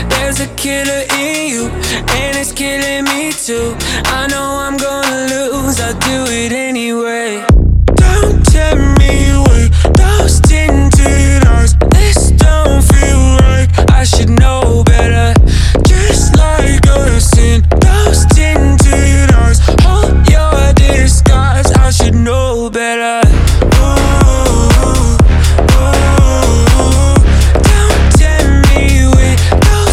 Genre: House